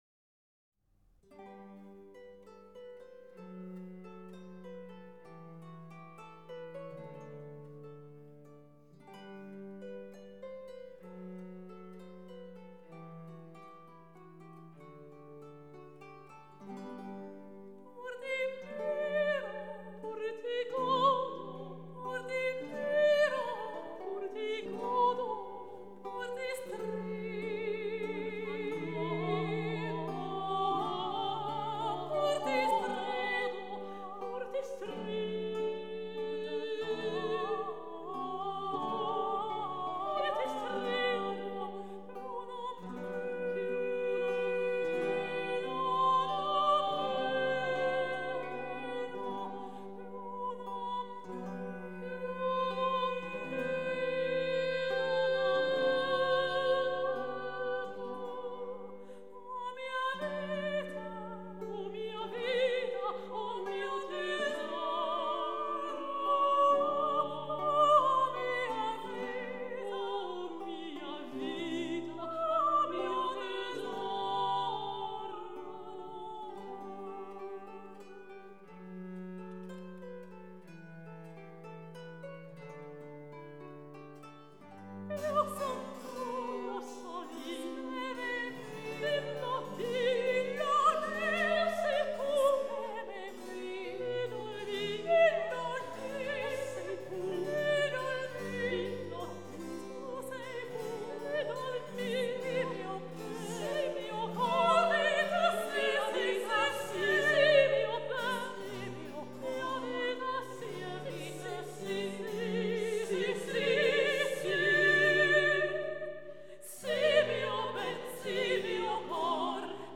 Chapter 9 "Monteverdi" Listen to Claudio Monteverdi (1567-1643) L'Incoronazione di Poppea (1641) Pur ti miro sung by real people.